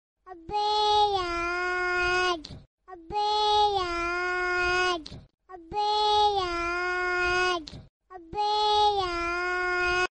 is an impressive song with a strong modern indie feel
Youthful melody, emotional lyrics
With a gentle melody mixed with a bit of innovation